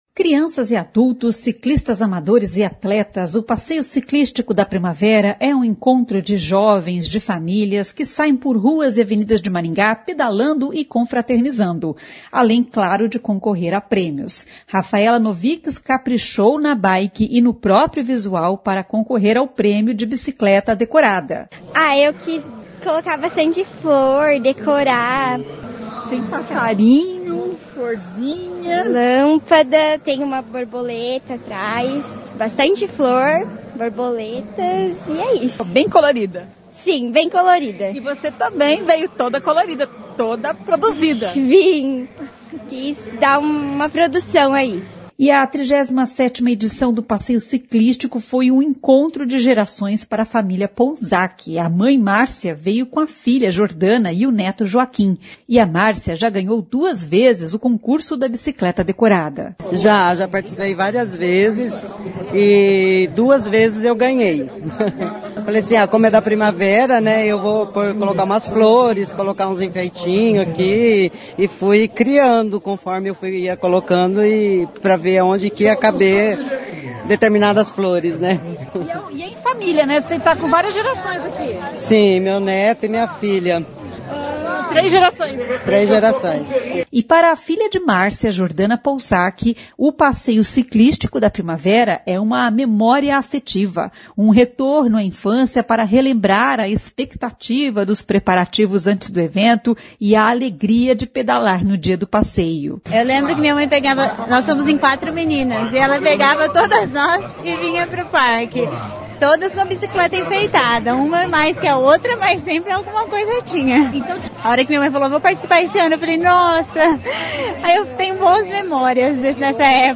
Milhares de ciclistas participaram do evento tradicional na cidade e que faz parte da memória afetiva de muitos maringaenses.